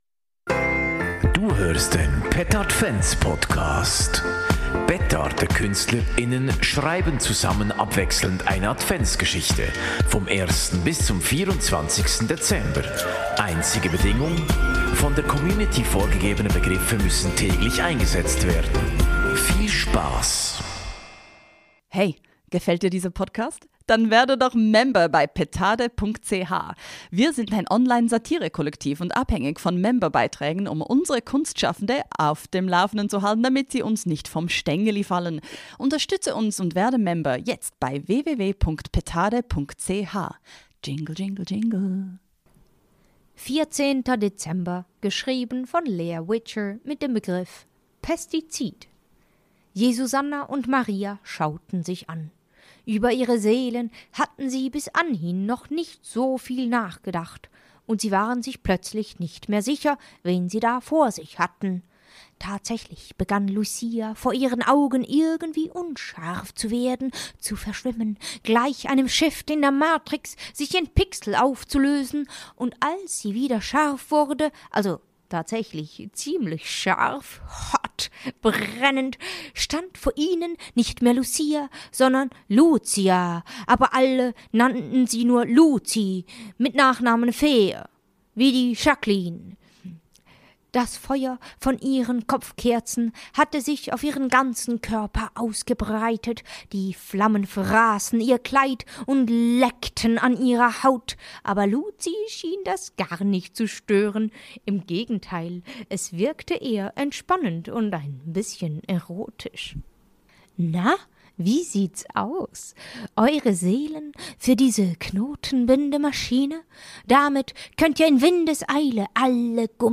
Petardekünstler:innen schreiben abwechselnd eine Adventsgeschichte